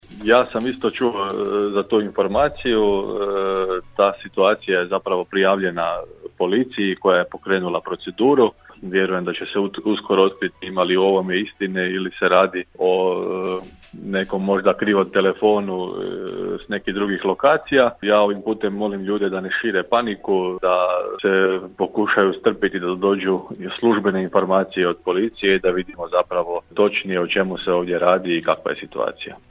U redakciju Podravskog radija (podaci poznati redakciji) stigla su upozorenja zabrinutih roditelja da se u blizini Osnovne škole u Đurđevcu i drugim lokacijama na kojima se kreću djeca na putu do i iz škole, a na našem području, pojavljuju sumnjiva kombi vozila stranih i zagrebačkih registracija koji navodno prate djecu. Nazvali smo gradonačelnika Grada Đurđevca Hrvoja Jančija koji nam je potvrdio da su i do njega stigle informacije o ovim nemilim događajima te da je o tome obavijestio policiju i moli da se ne širi panika dok se ne dobiju službene informacije o točnosti ovih navoda: